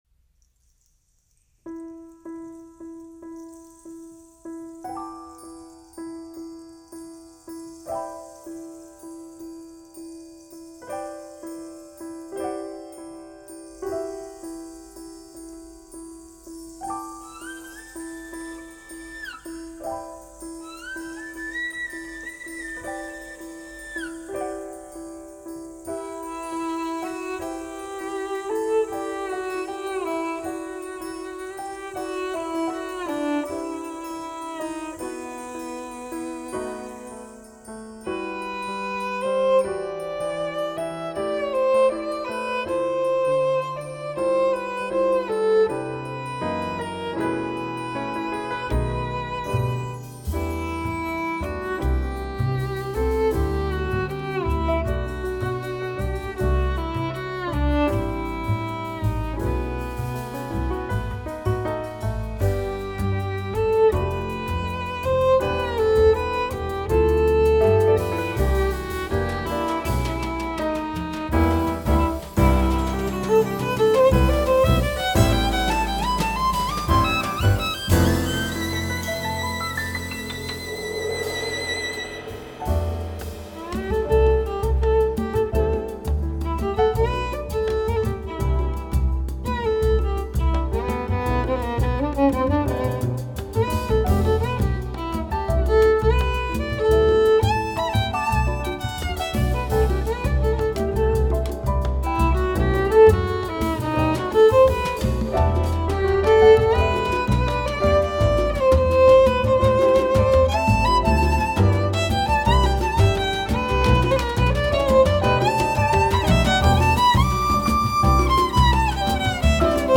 Genre: Classic